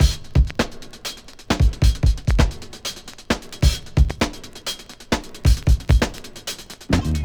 • 133 Bpm Drum Beat F Key.wav
Free drum loop sample - kick tuned to the F note. Loudest frequency: 1200Hz
133-bpm-drum-beat-f-key-Btl.wav